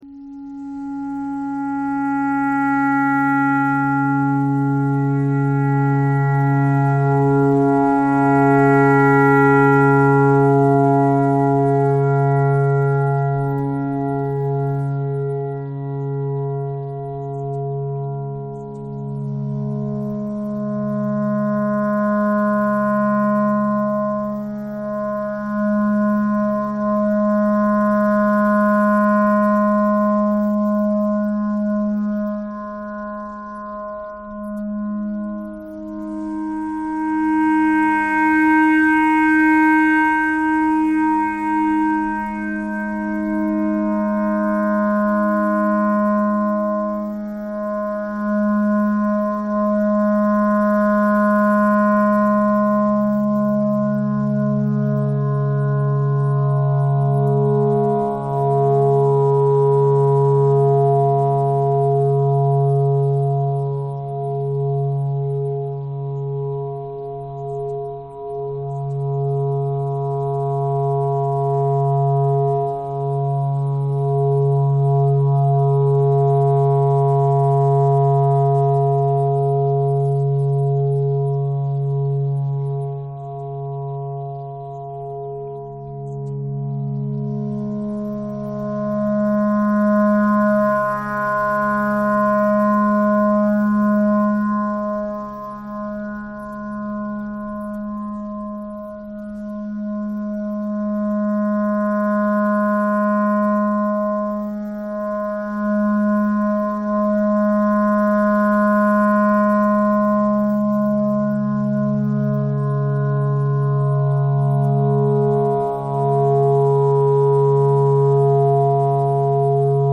::: an exercise in spinning thread from recorded sound fragments & then weaving these individual strands into twisted cords ::: an exercise in chance-infused, text-based, generative composition.